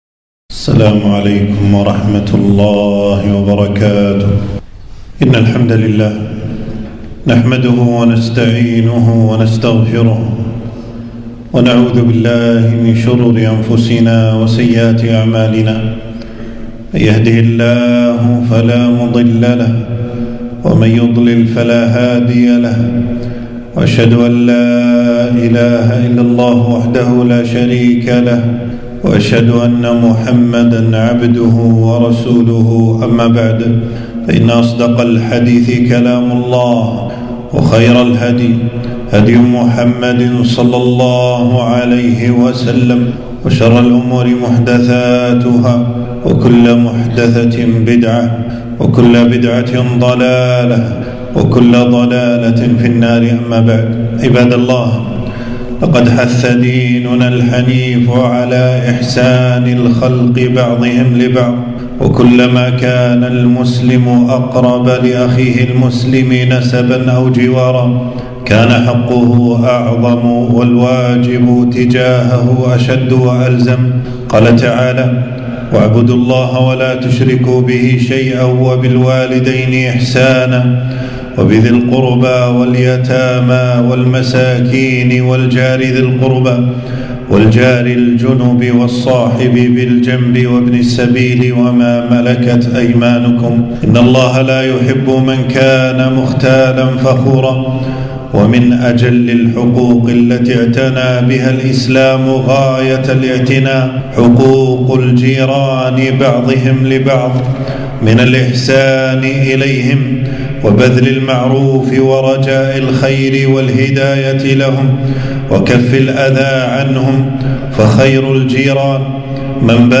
خطبة - الإحسان إلى الجيران سبيل أهل الإيمان